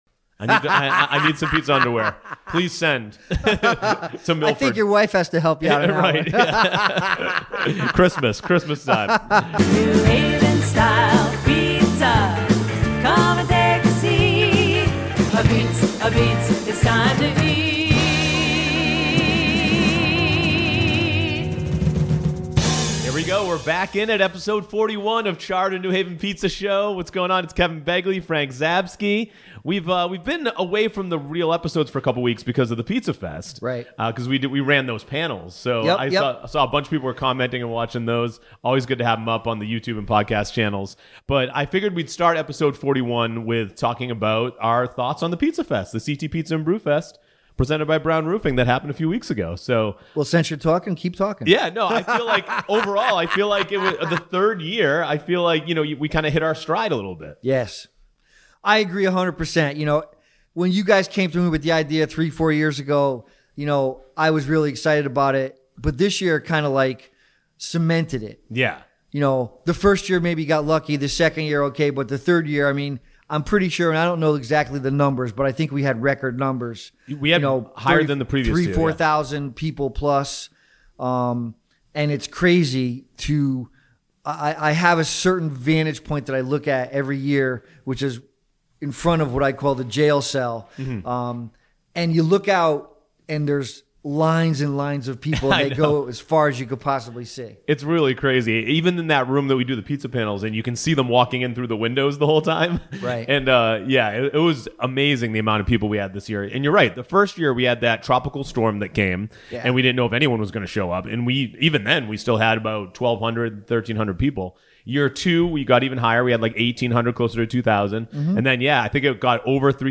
Charred is a New Haven Style Pizza centric talk show that runs two shows a month.
Monthly episodes feature different pizza industry guests from the New Haven and Connecticut pizza scene. Plus discussions, debate, and news about all things pizza.